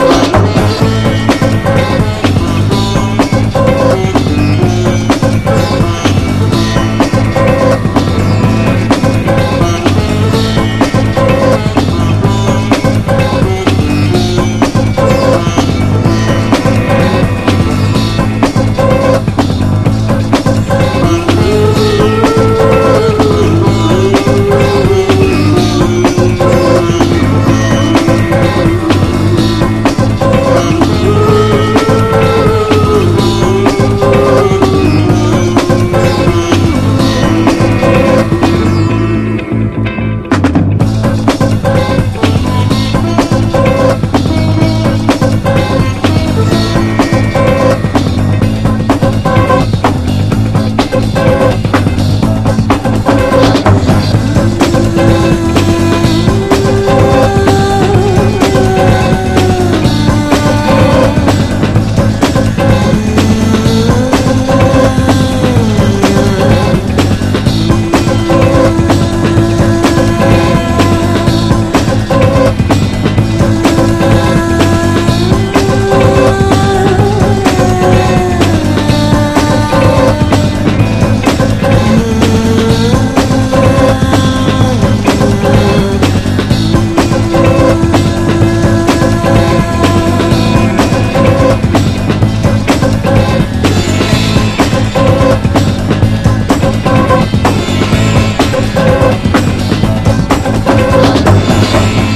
美しいギター・カッティングで幕開けるブラジリアン・ダンサー